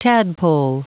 Prononciation du mot tadpole en anglais (fichier audio)
Prononciation du mot : tadpole